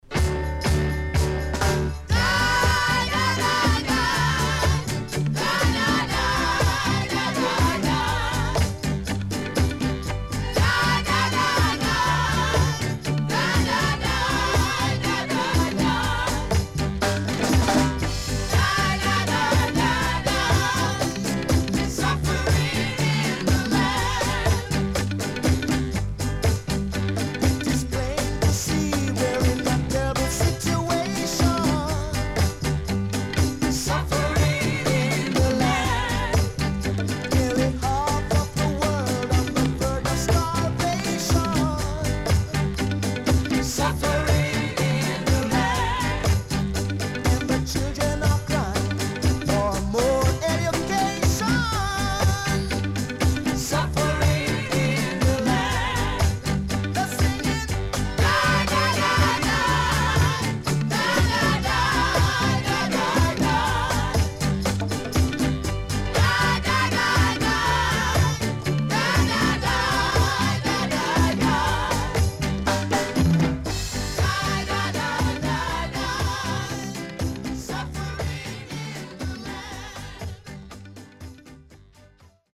HOME > REGGAE / ROOTS
SIDE A:少しチリノイズ入ります。